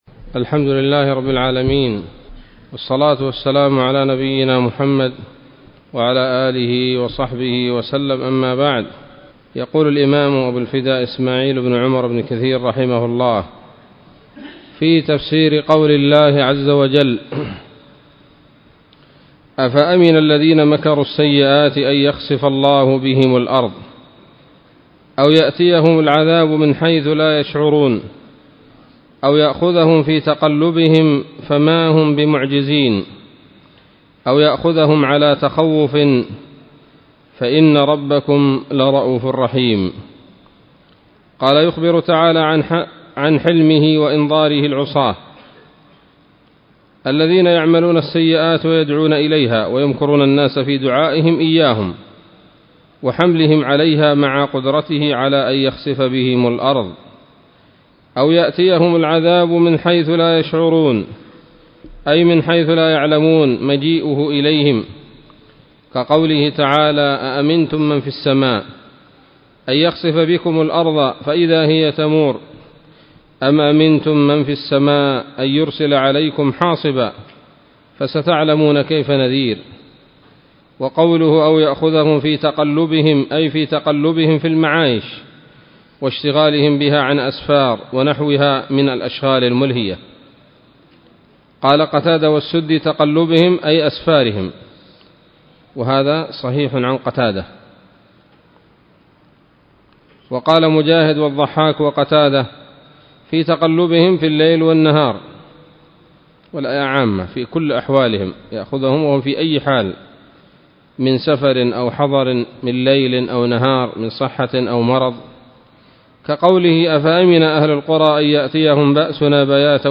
الدرس السابع من سورة النحل من تفسير ابن كثير رحمه الله تعالى